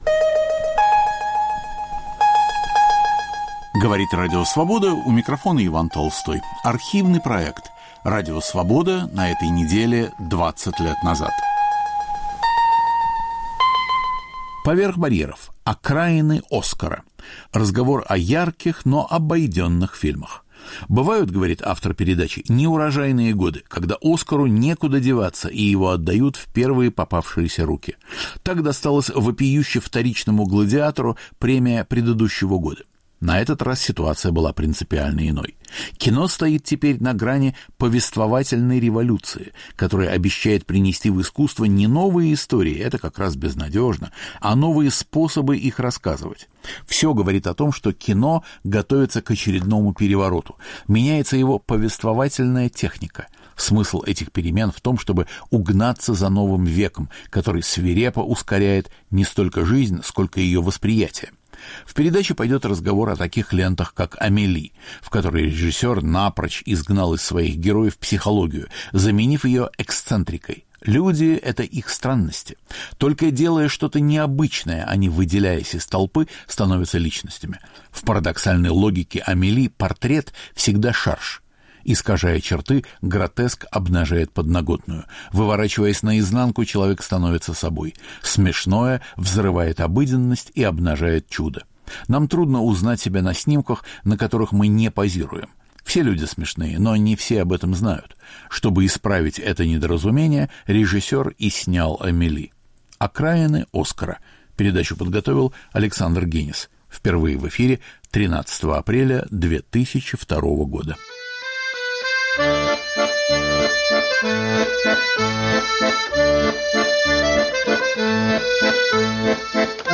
Автор и ведущий Александр Генис.